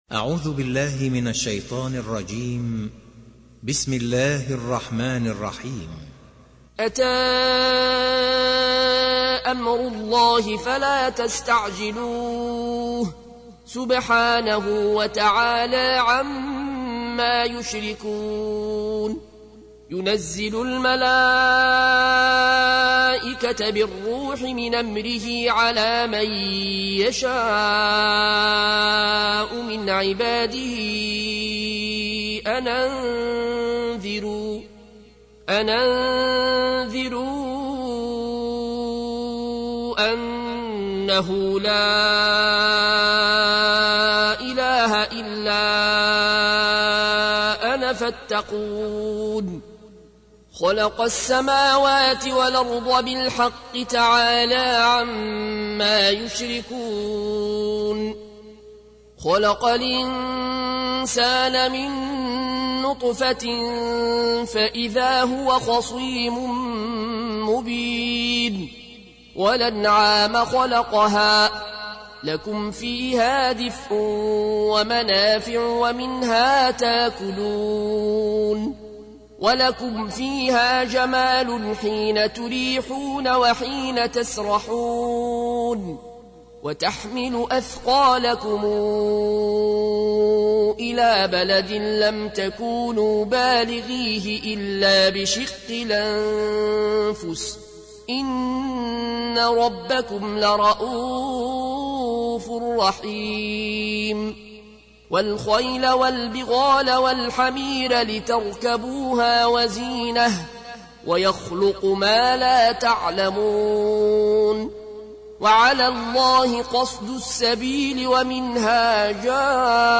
تحميل : 16. سورة النحل / القارئ العيون الكوشي / القرآن الكريم / موقع يا حسين